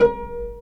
Index of /90_sSampleCDs/Roland - String Master Series/STR_Vcs Marc-Piz/STR_Vcs Pz.3 dry